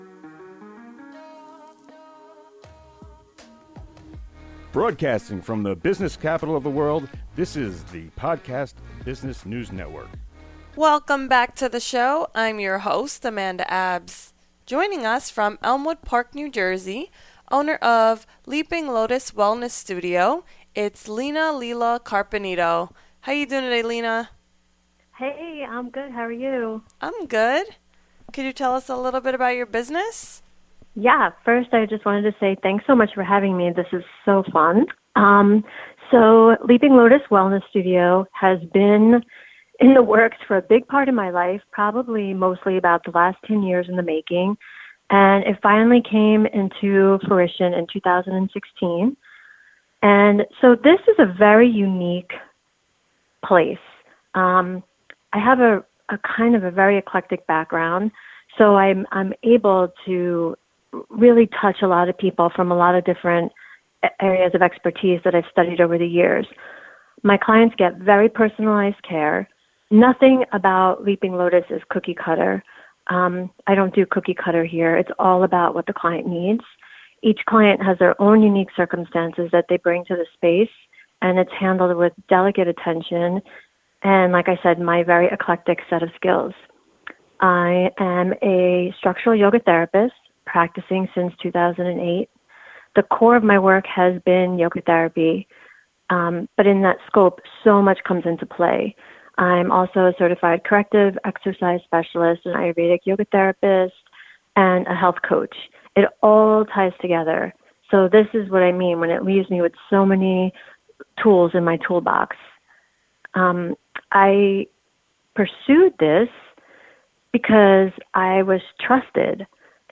So excited to share this ~ I was asked to do a podcast interview with TuneIn Radio.